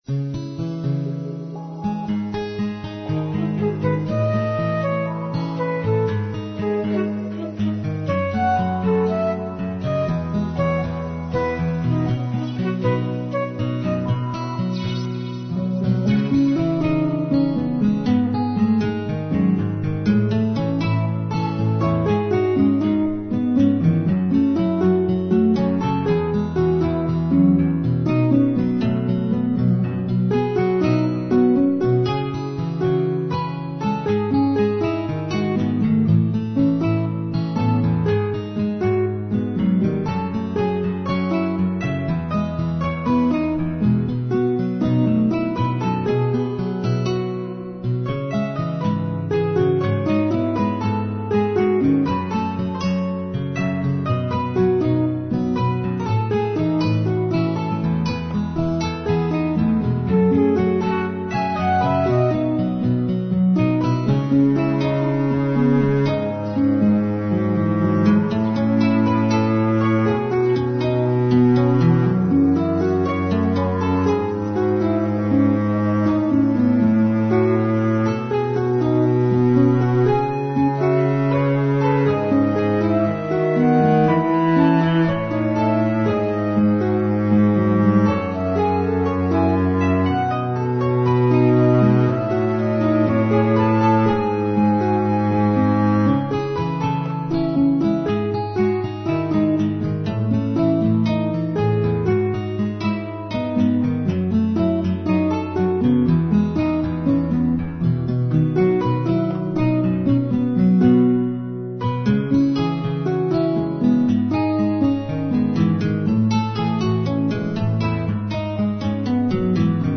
Sentimental Instrumental Guitar Ballad